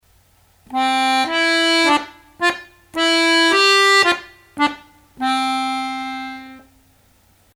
Ligado- Picado: unha liña curva con punto ao final
do-mi-re ligadura cun punto no re; mi-sol-fa ligarua cun punto no fa